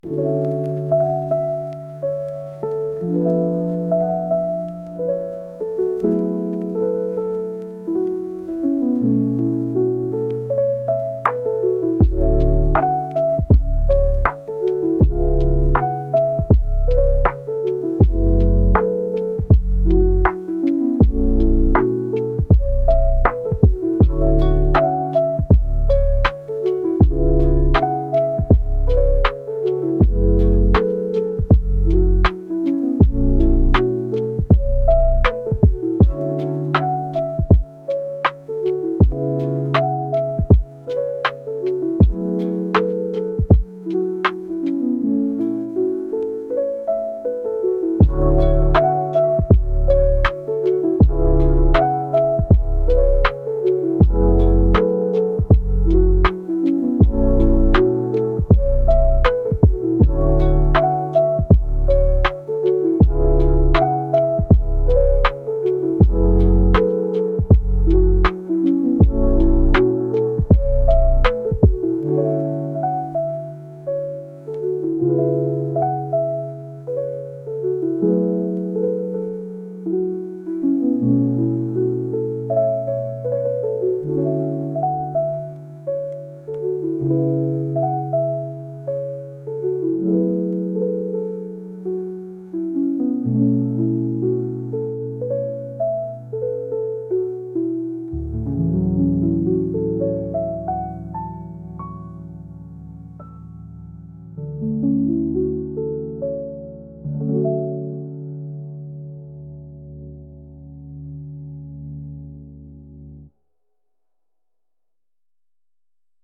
Lofi-medit.mp3